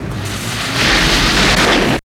51 WIND   -L.wav